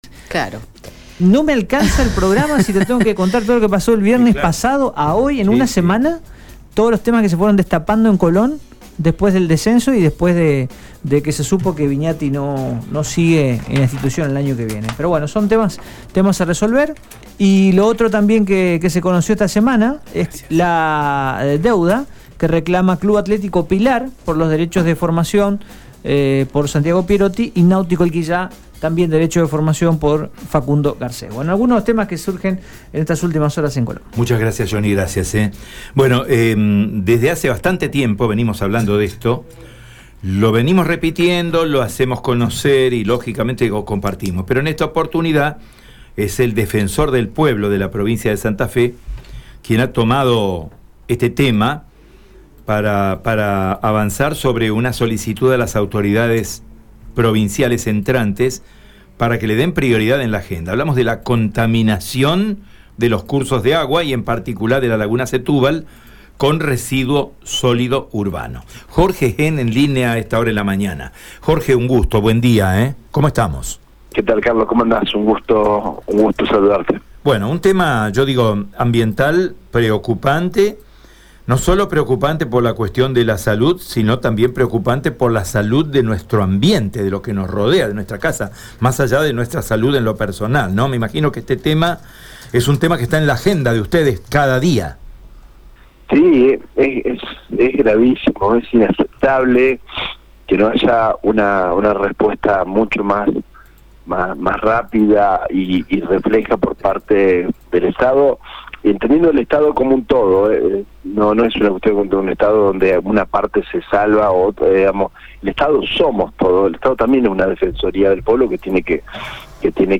Escucha la palabra de Jorge Henn en Radio EME: